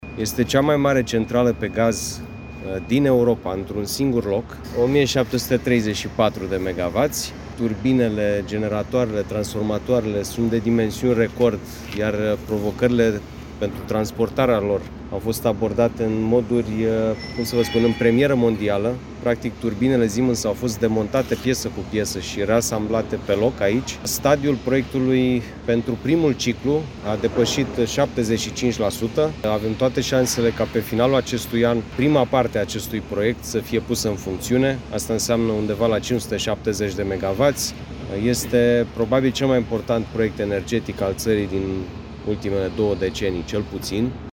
Prima turbină, de 570 de megawați, este estimată să funcționeze undeva la finalul anului, iar cea de a doua, la aproximativ o lună distanță, spune ministrul Energiei, Sebastian Burduja, prezent pe șantier.